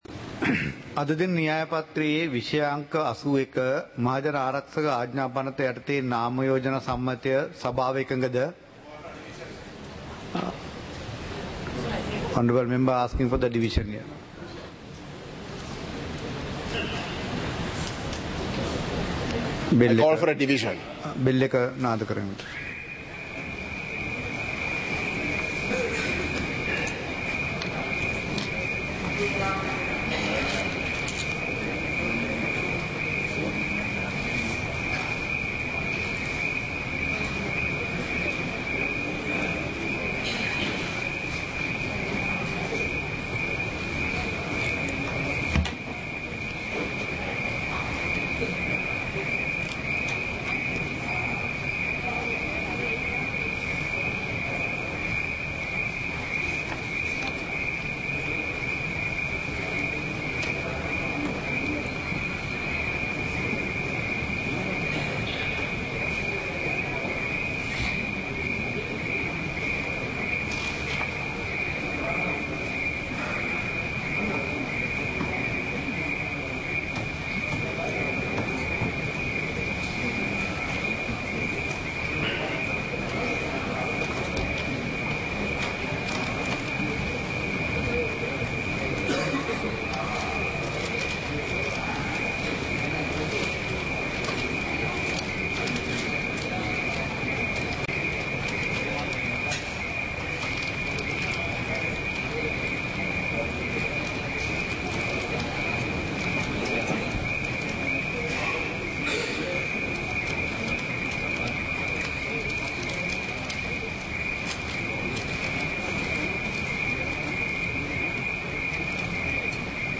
சபை நடவடிக்கைமுறை (2026-03-06)
நேரலை - பதிவுருத்தப்பட்ட